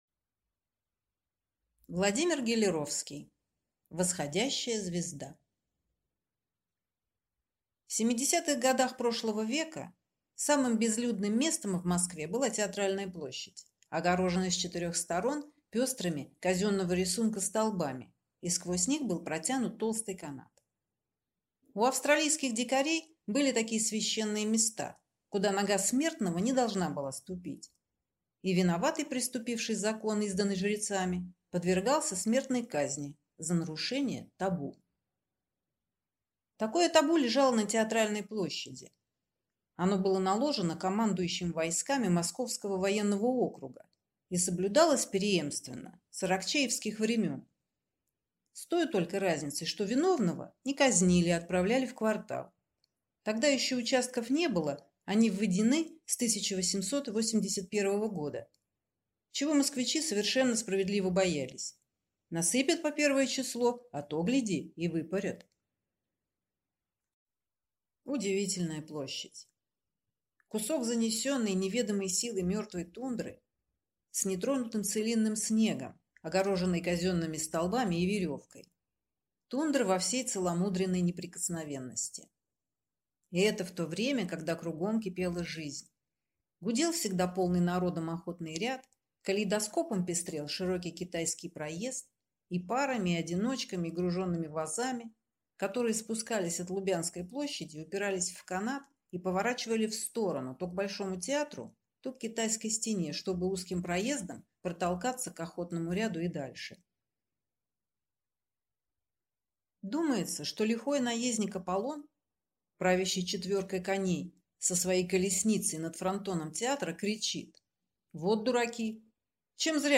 Аудиокнига Восходящая звезда | Библиотека аудиокниг
Прослушать и бесплатно скачать фрагмент аудиокниги